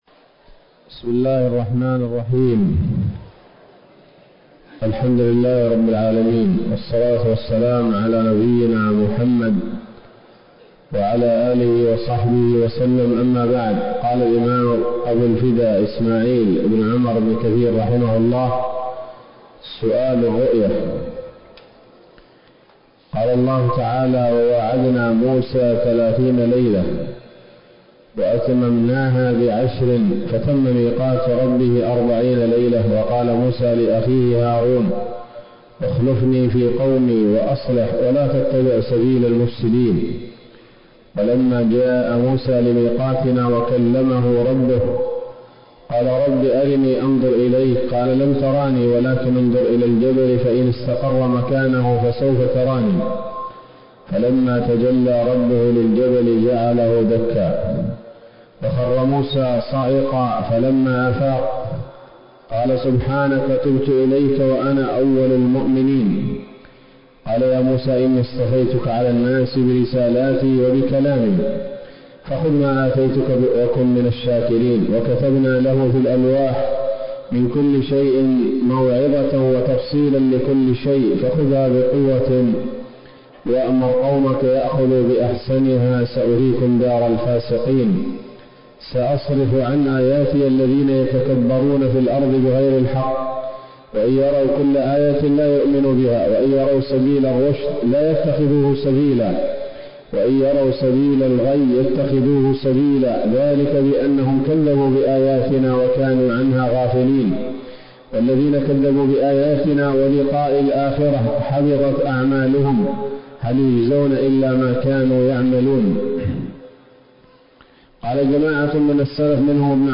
‌‌الدرس السابع والتسعون من قصص الأنبياء لابن كثير رحمه الله تعالى